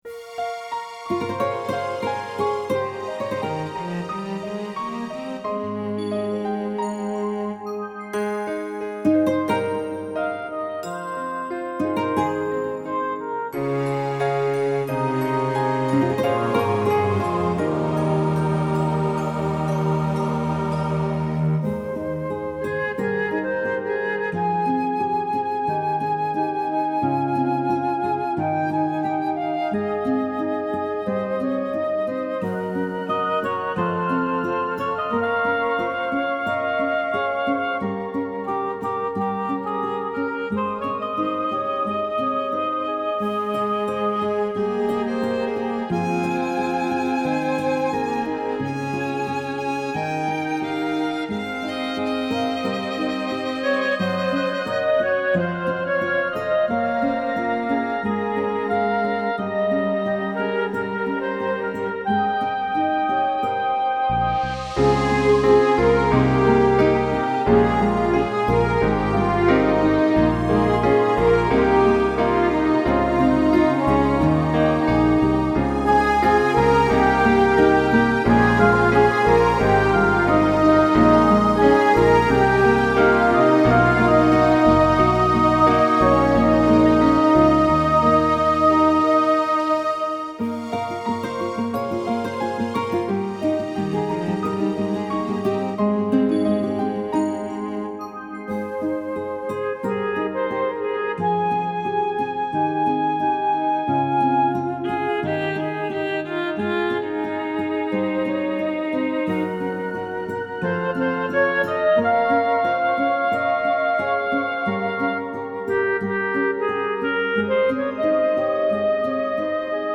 Song of Joy Home Recording 7-20-16
SONG OF JOY INSTRUMENTAL